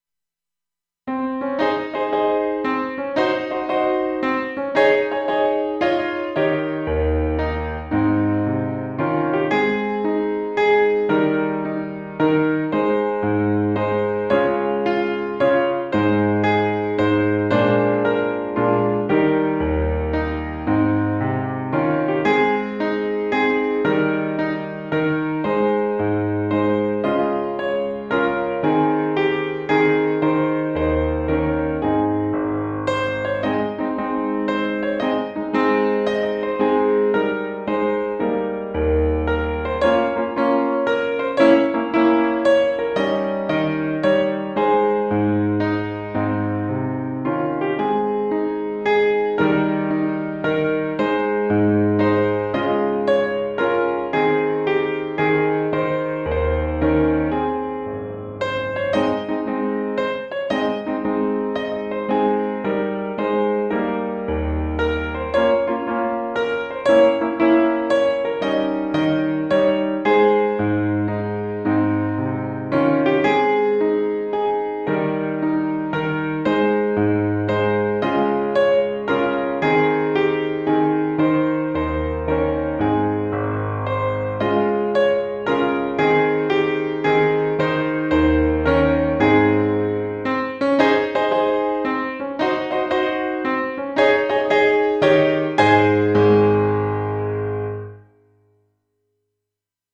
Easy intermediate arrangement
hymn tune